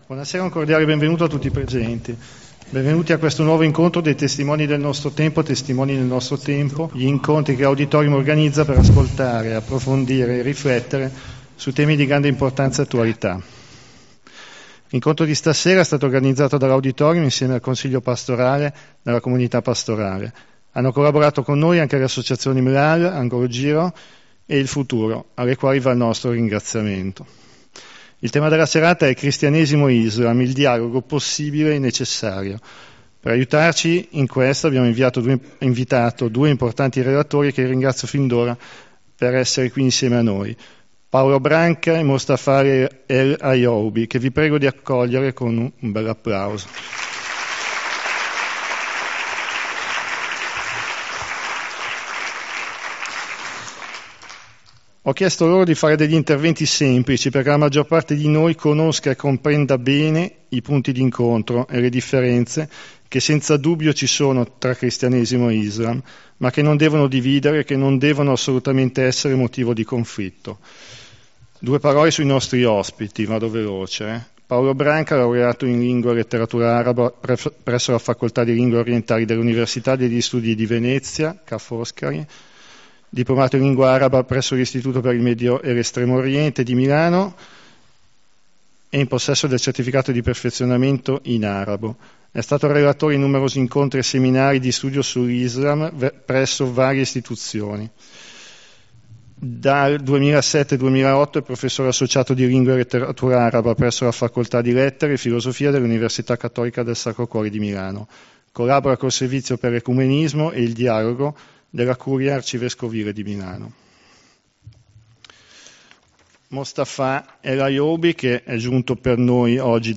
Ascolta la registrazione della serata